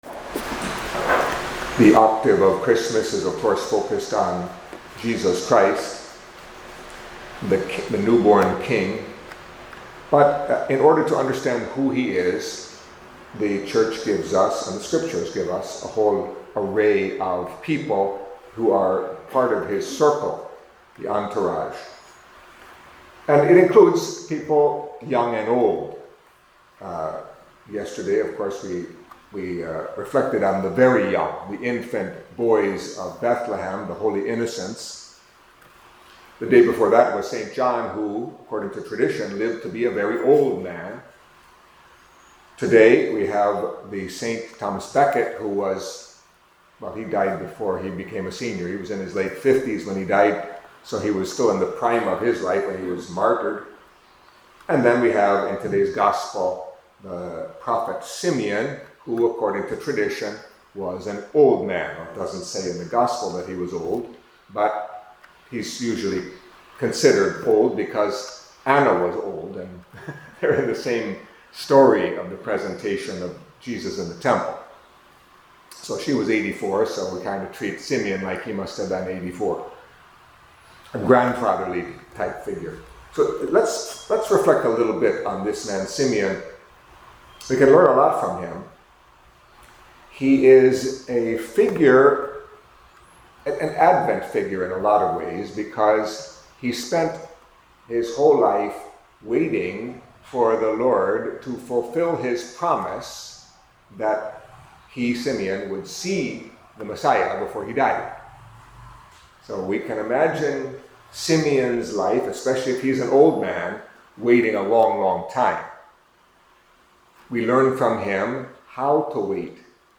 Catholic Mass homily for the Fifth Day in the Octave of Christmas